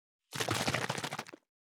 363,500のペットボトル,ペットボトル振る,ワインボトルを振る,水の音,ジュースを振る,シャカシャカ,カシャカシャ,チャプチャプ,ポチャポチャ,シャバシャバ,チャプン,
ペットボトル